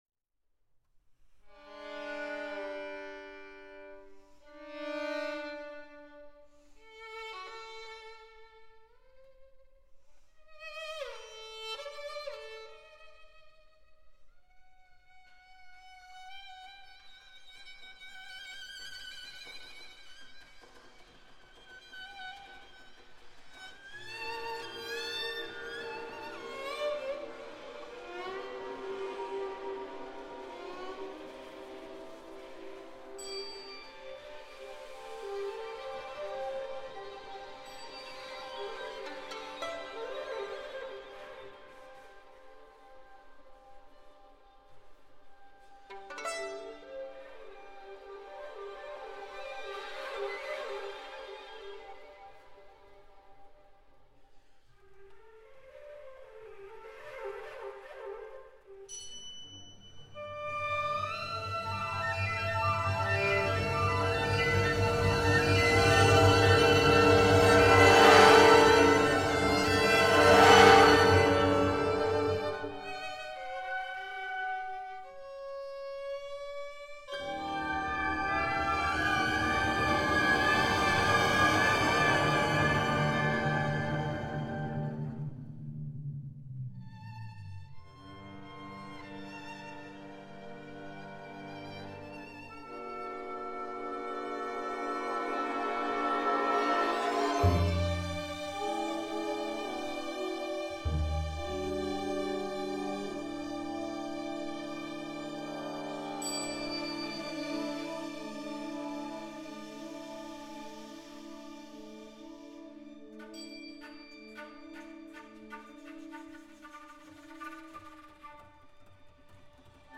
violin soloist